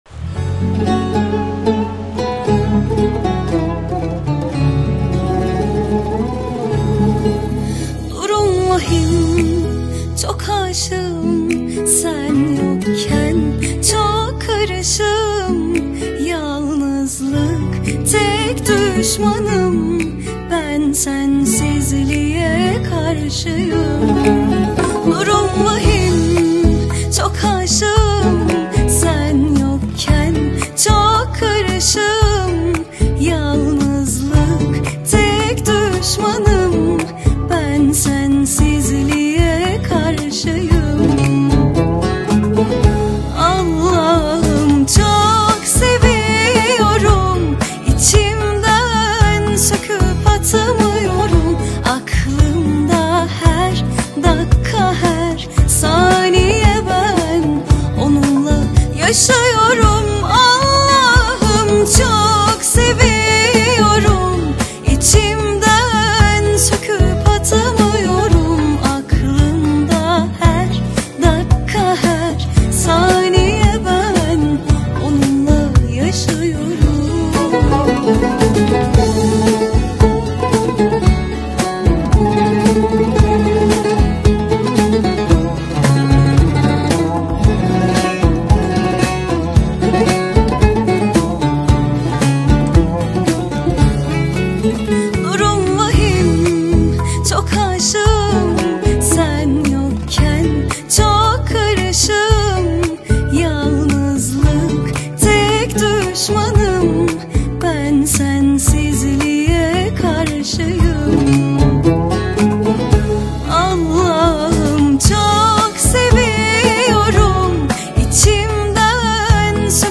Турецкие песни